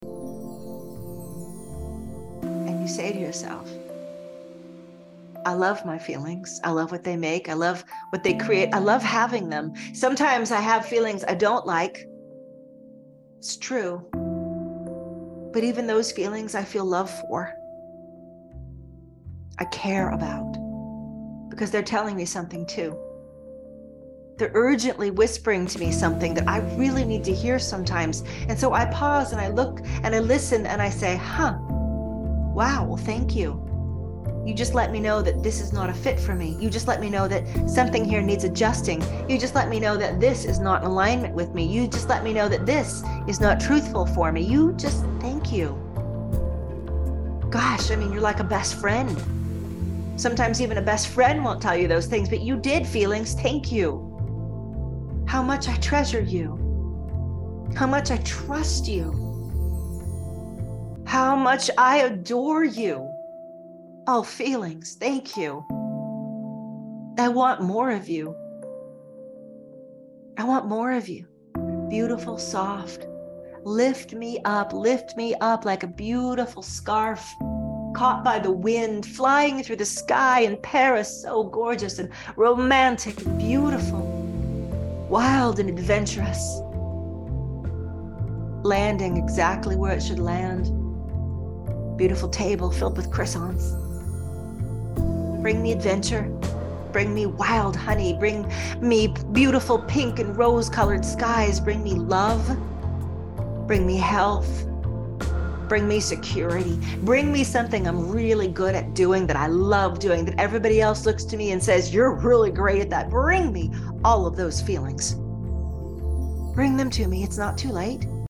One Brilliant Thing (Live Set 2023)
Experience the five BEST recordings from our live 2023 summertime "One Brilliant Thing" event. Each Flowdream focuses on a special aspect of your heart's desire.